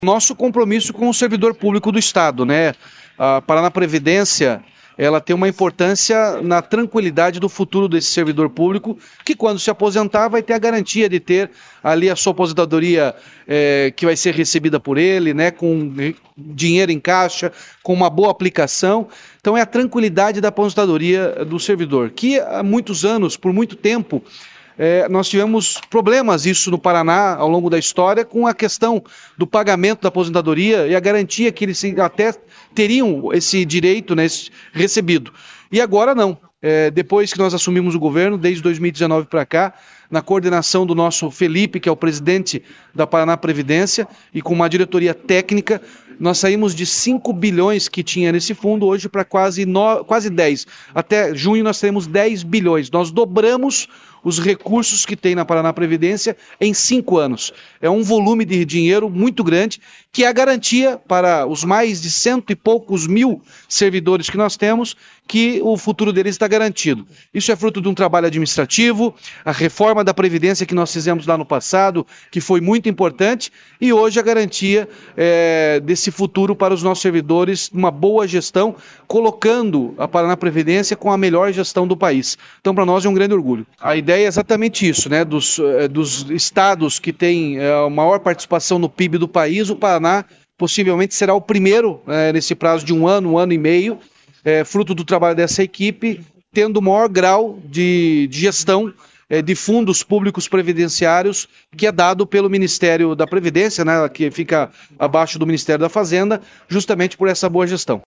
Sonora do governador Ratinho Junior sobre a conquista do Nível III por parte da Paranaprevidencia no Programa Pró-Gestão do governo federal